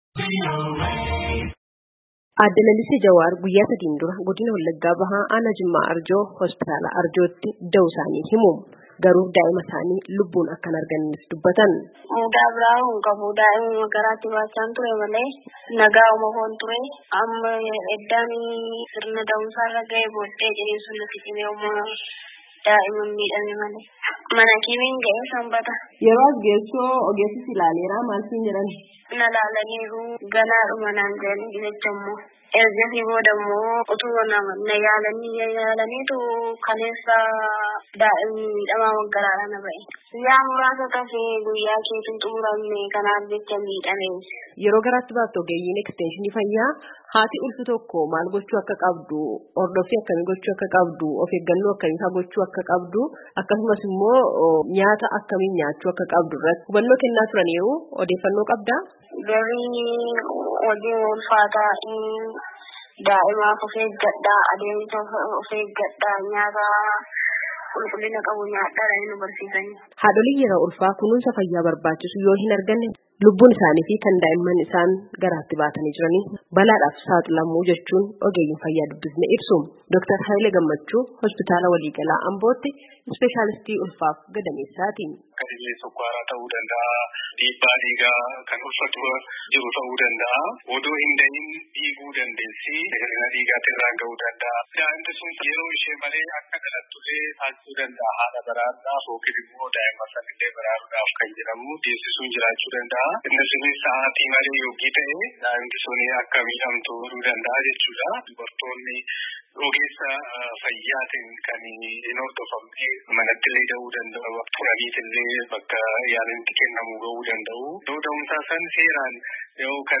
Haadholiin Sagaaleen Ameerikaa dubbises sababaalee adda addaatiin yeroo da’umsaa lubbuun haadholii fi daa’immanii akka dabru dubbatu.